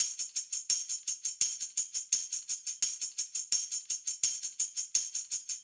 Index of /musicradar/sampled-funk-soul-samples/85bpm/Beats
SSF_TambProc1_85-01.wav